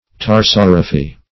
Search Result for " tarsorrhaphy" : The Collaborative International Dictionary of English v.0.48: Tarsorrhaphy \Tar*sor"rha*phy\, n. [Tarsus + Gr.